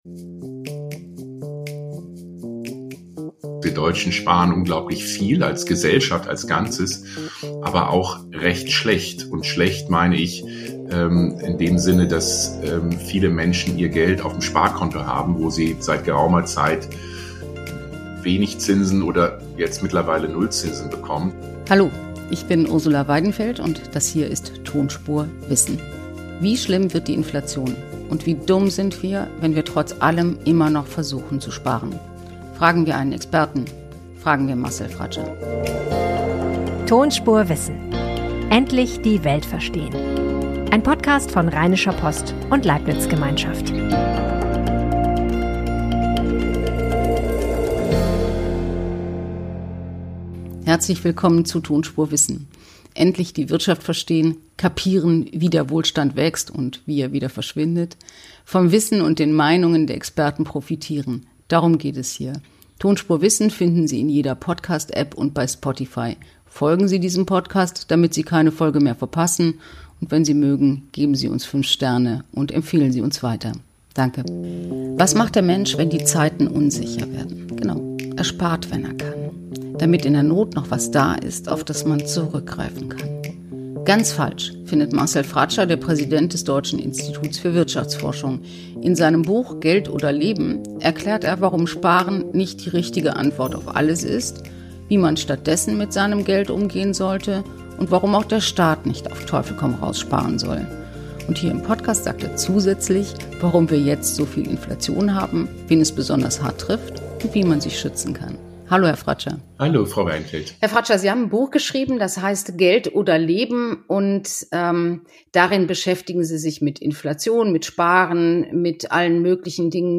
Geld zur Seite legen, damit noch etwas da ist, wenn es noch schlimmer kommt - ist das sinnvoll? Das Fragen wir den Chef des Deutschen Instituts für Wirtschaftsforschung, Marcel Fratzscher.